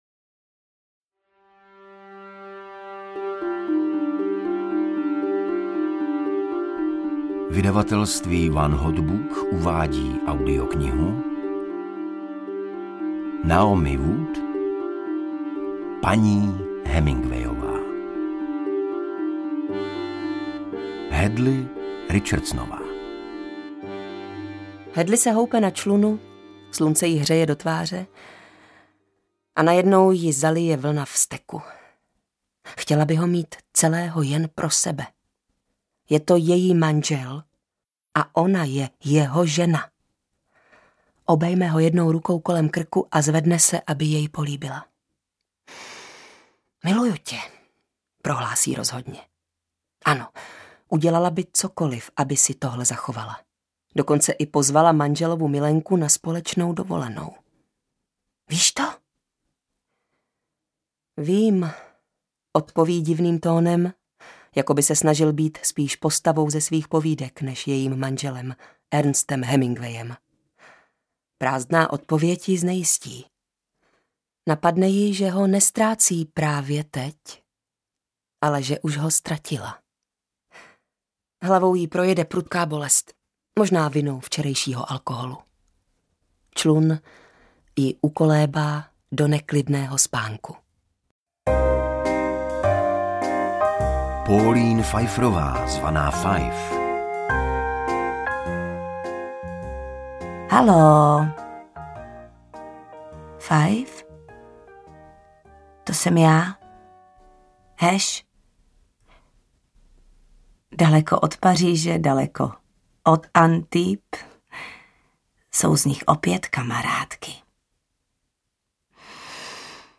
Paní Hemingwayová audiokniha
Ukázka z knihy
Příběh vyprávěný čtyřmi hlasy, spředený z dochovaných vášnivých dopisů, deníkových zápisů, pamětí, telegramů…
• InterpretTaťjana Medvecká, Igor Bareš, Jana Stryková, Dana Černá, Petra Špalková